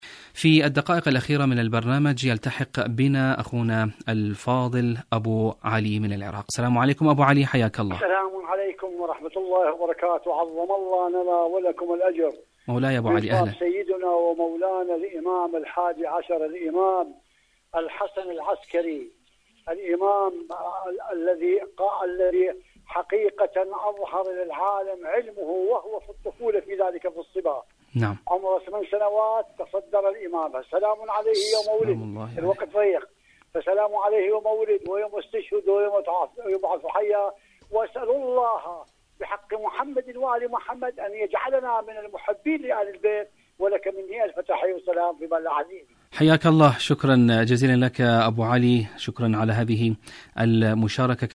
مشاركة هاتفية